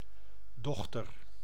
Ääntäminen
IPA : /ˈdɔːtə(ɹ)/